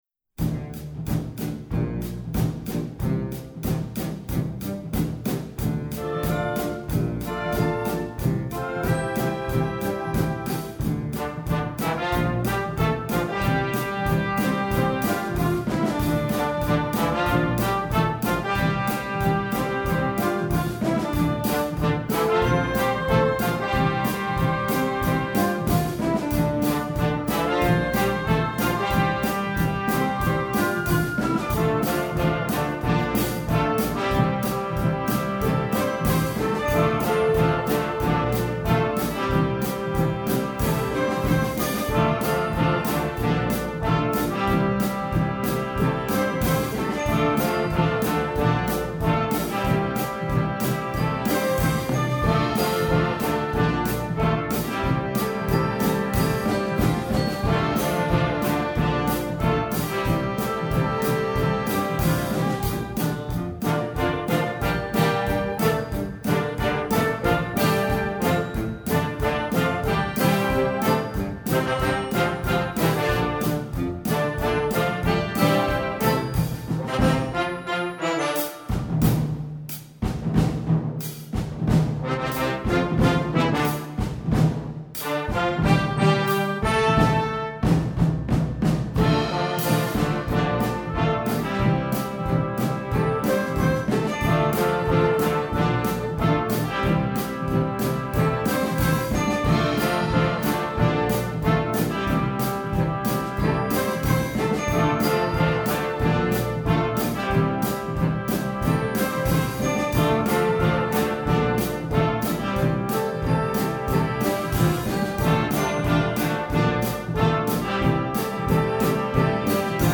Instrumentation: concert band
pop, rock, instructional, children